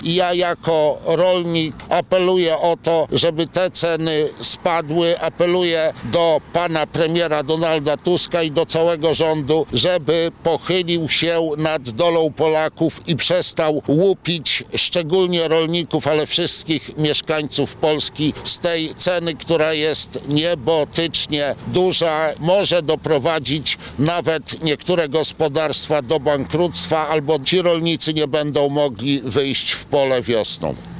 Przed stacją paliw Orlenu w Zamościu odbyła się konferencja prasowa przedstawicieli lokalnych struktur Prawa i Sprawiedliwości.